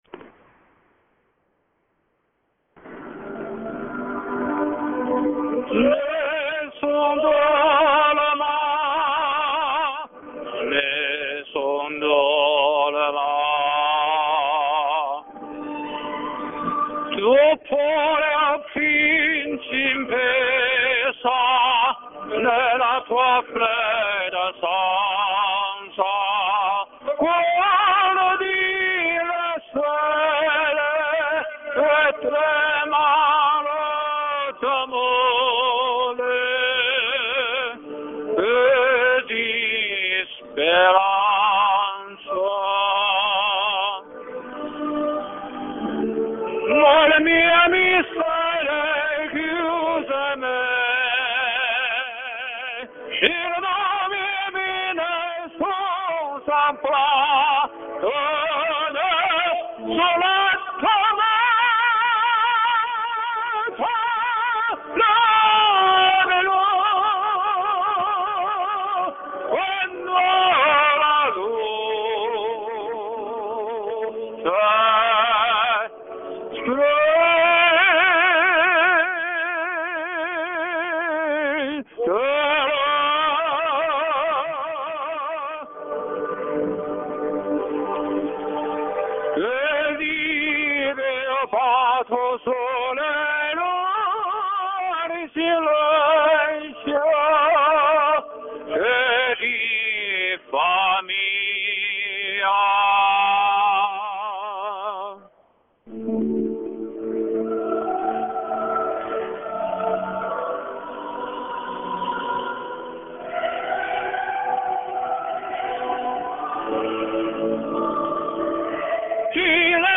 《走调大赛》意大利咏叹调≪今夜无人入睡≫
这个是俺一边开车一边嚎的，录音效果不好。再加上是坐着唱的，最后差一点没憋死俺，周末凑个热闹，也送给珍珠湾一周年-----唱的是老帕的经典≪今夜无人入睡≫，走调是肯定的，还有，声音大了也怕吓着你，大周末的，扰民。
不错的走调,韵味很浓,声音很宏亮!